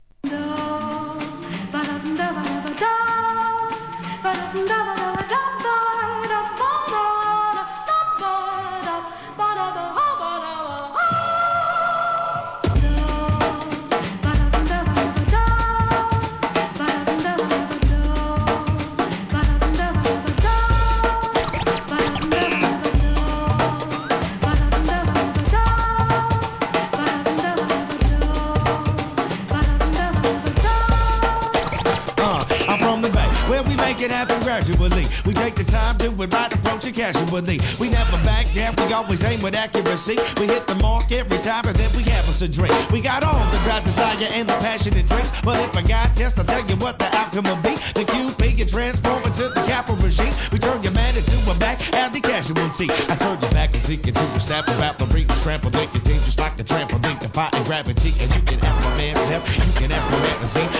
HIP HOP/R&B
エスニックなパーカッションに「シャバダバダ〜♪」印象的なB/Wも!!